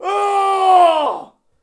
battle-cry-6.mp3